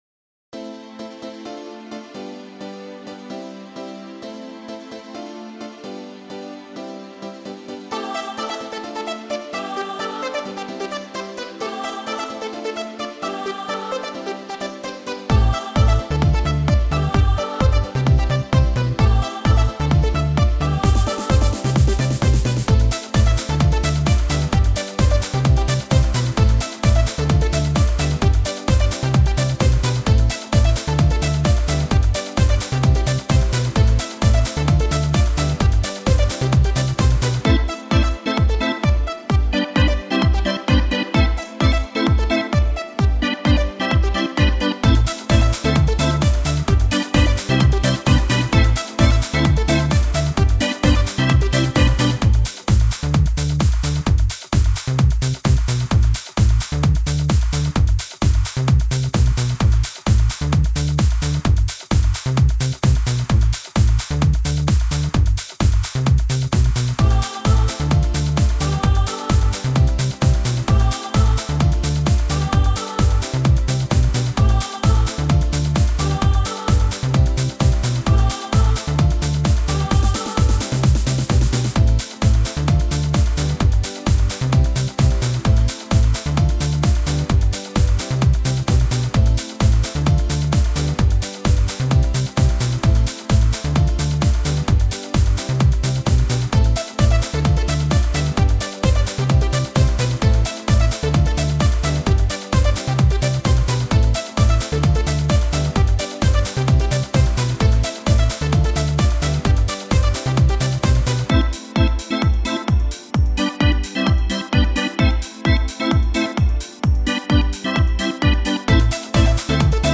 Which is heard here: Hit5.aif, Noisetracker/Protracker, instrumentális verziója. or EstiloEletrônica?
instrumentális verziója.